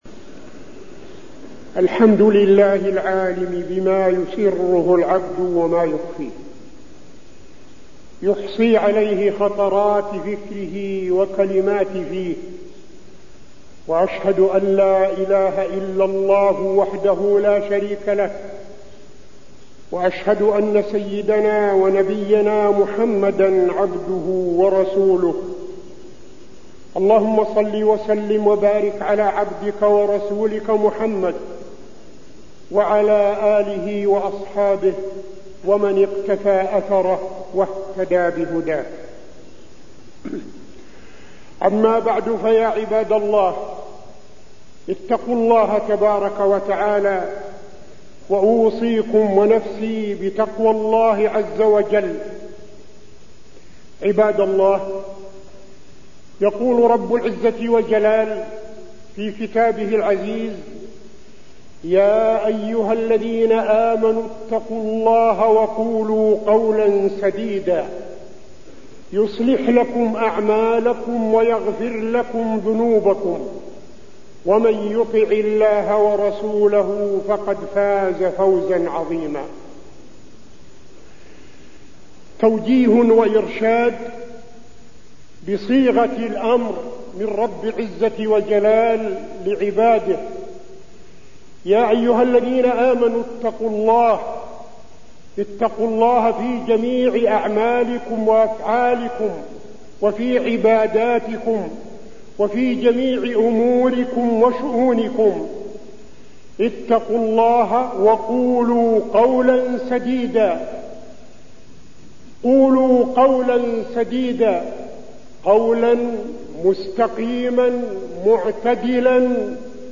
تاريخ النشر ٢٥ رجب ١٤٠٦ هـ المكان: المسجد النبوي الشيخ: فضيلة الشيخ عبدالعزيز بن صالح فضيلة الشيخ عبدالعزيز بن صالح حفظ اللسان